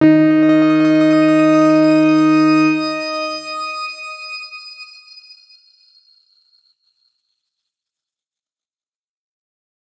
X_Grain-D#3-ff.wav